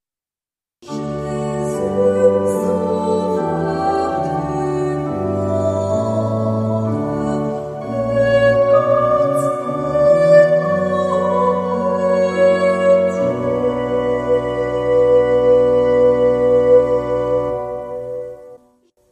Refrain : Jésus sauveur du monde